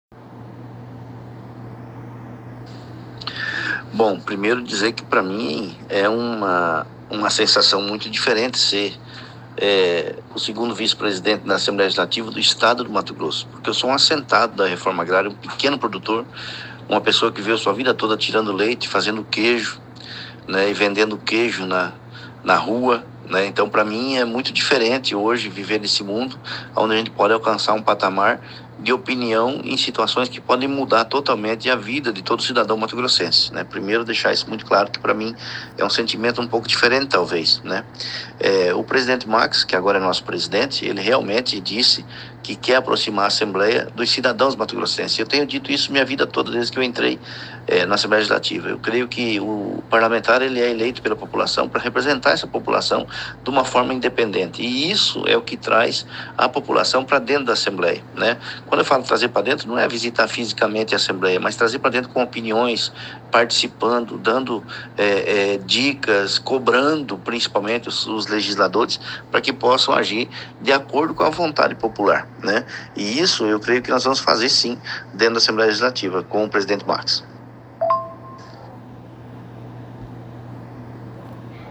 OUÇA NA ÍNTEGRA A ENTREVISTA QUE O SITE OPINIÃO FEZ COM O DEPUTADO GILBERTO CATTANI